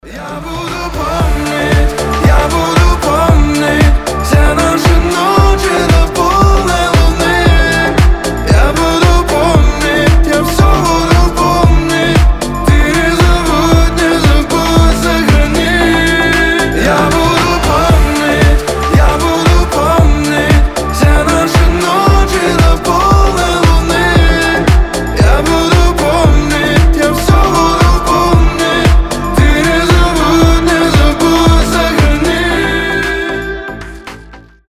• Качество: 320, Stereo
мужской голос